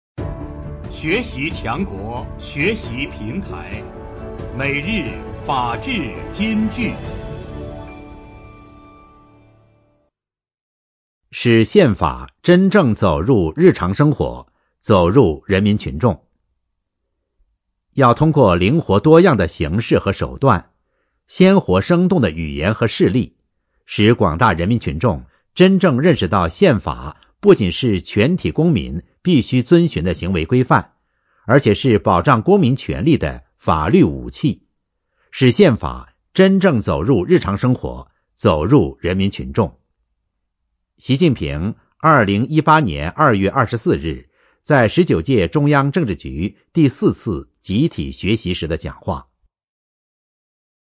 每日法治金句（朗读版）|使宪法真正走入日常生活、走入人民群众 _ 学习宣传 _ 福建省民政厅